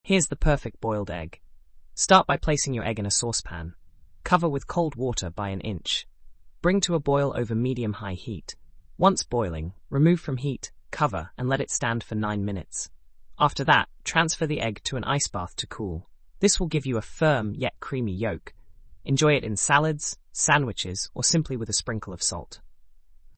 In addition, text to speech (voice). Six available voices with ultra realistic speech.
recipe-fable.mp3